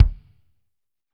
Index of /90_sSampleCDs/Northstar - Drumscapes Roland/DRM_Pop_Country/KIK_P_C Kicks x